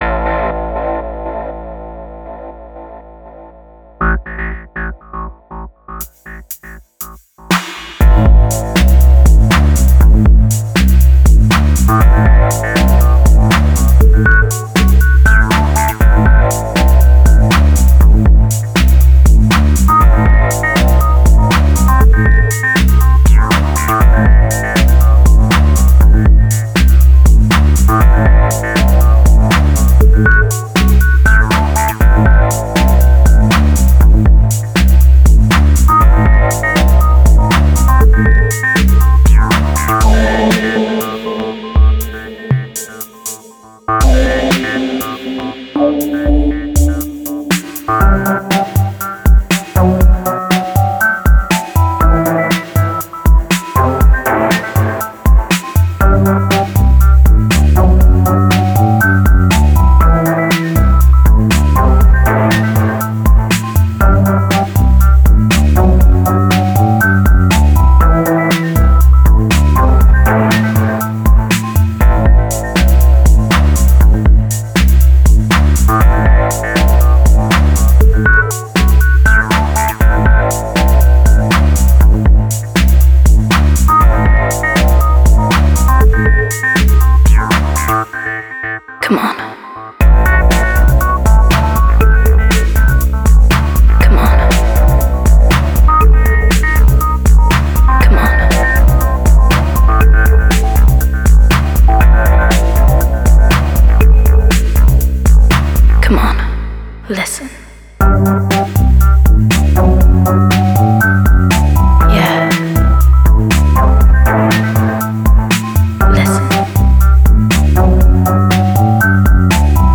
Genre Downbeat